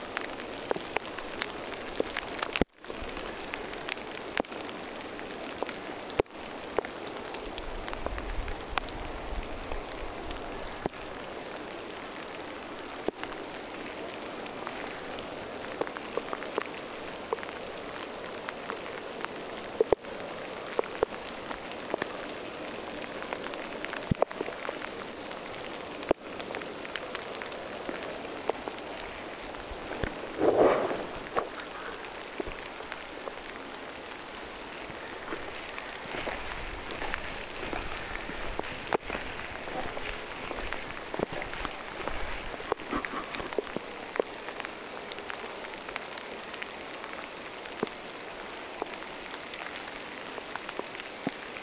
Luontopolulla kuului ilmeisesti
kolmen parven ääntä.
pyrstötiaiset matkaavat sateessakin?
pyrstotiaiset_matkaavat_sateessakin.mp3